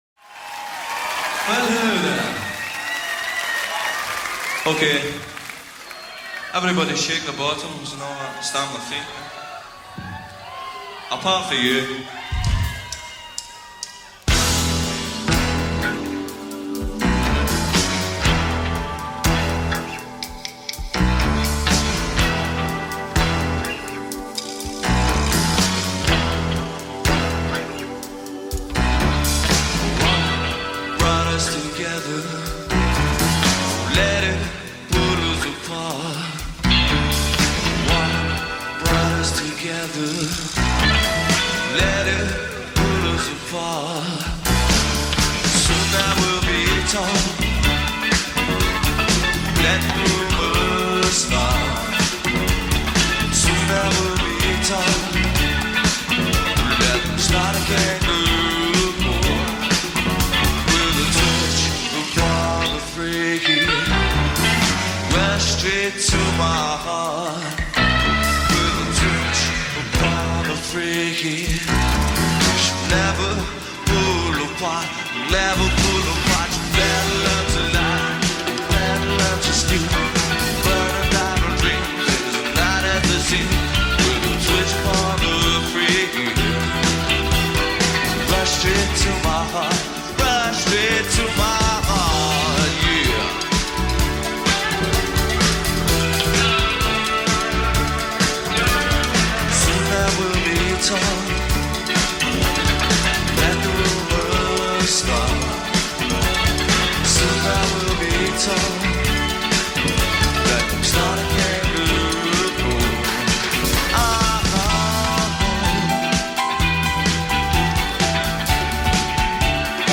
bass
vocals
guitar
drums